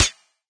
metal.ogg